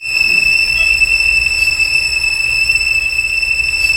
Index of /90_sSampleCDs/Roland LCDP13 String Sections/STR_Symphonic/STR_Symph. Slow